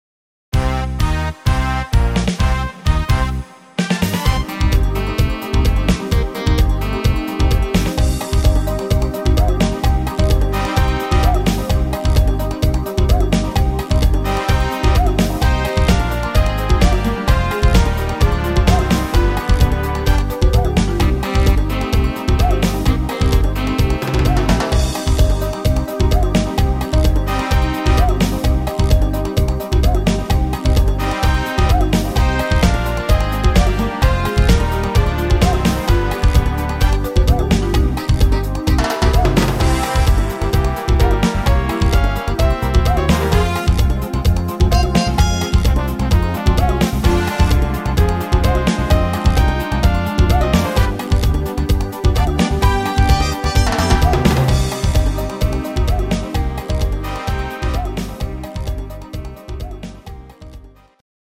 instrumtal Orchester